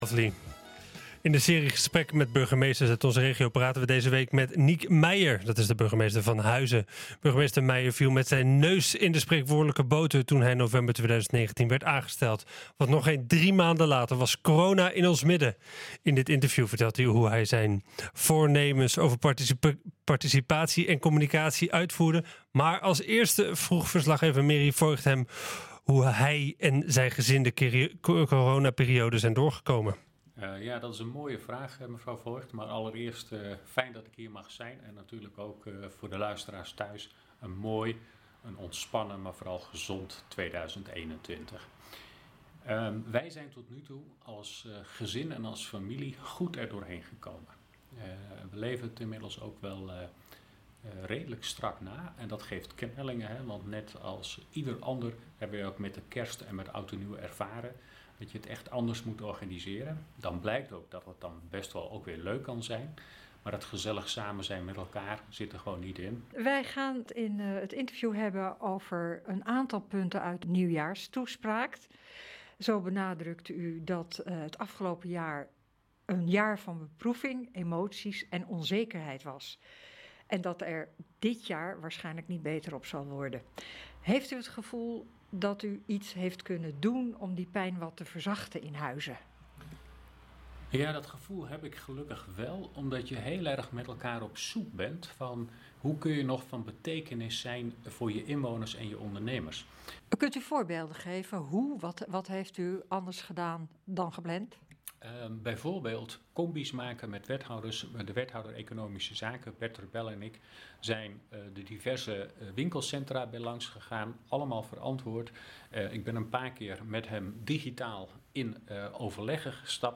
In de serie gesprekken met Burgemeesters uit onze regio praten we deze week met Niek Meijer, Burgemeester van Huizen.